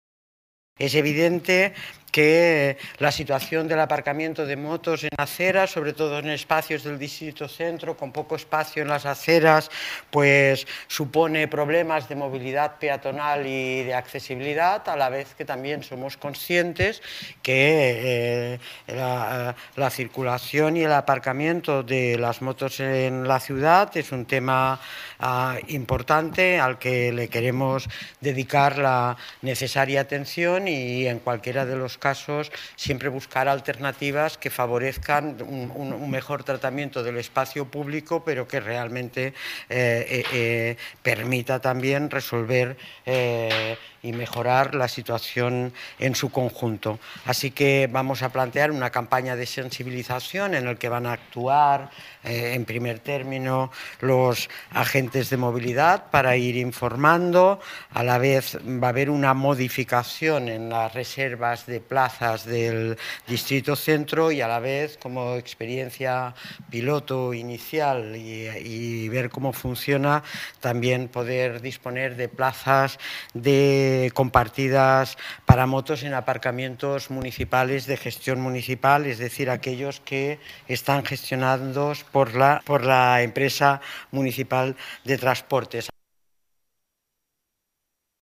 López Carmona señala que se trata de una campaña orientada a la convivencia vial Inés Sabanés habla sobre la situación del aparcamiento de motos en la acera y la movilidad de los peatones García Castaño habla sobre la calidad del espacio público